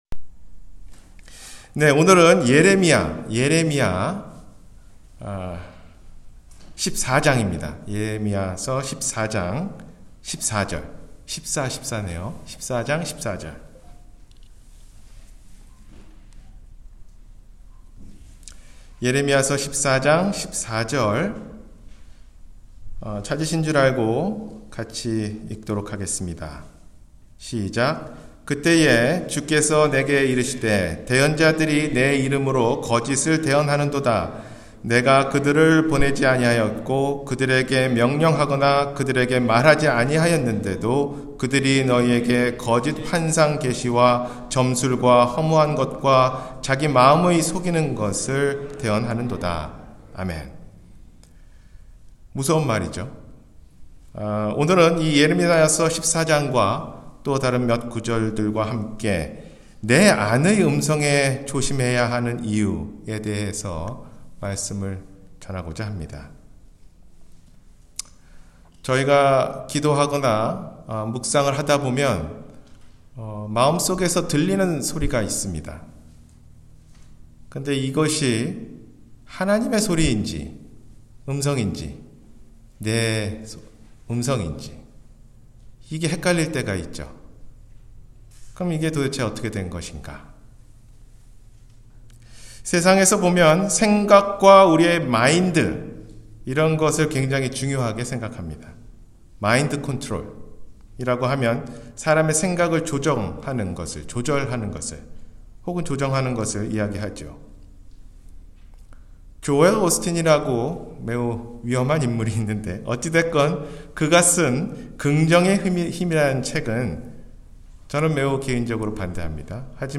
내 안의 음성에 조심해야하는 이유 – 주일설교